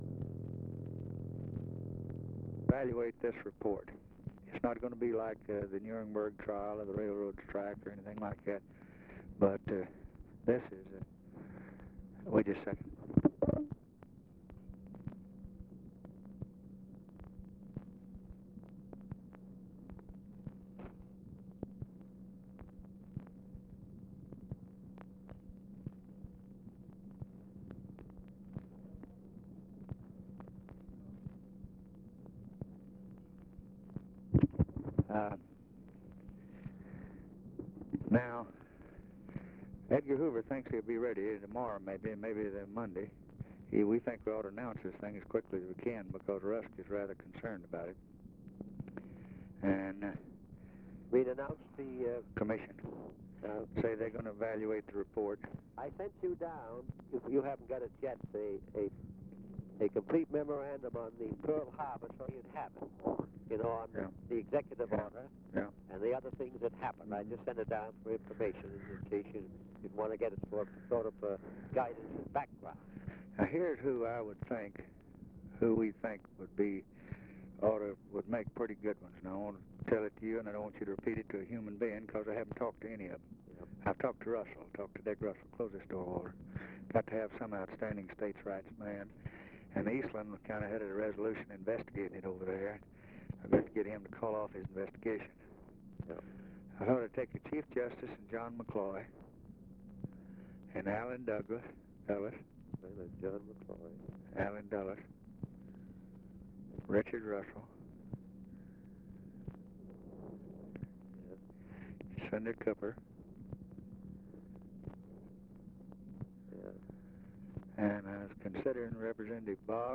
Conversation with JOHN MCCORMACK, November 29, 1963
Secret White House Tapes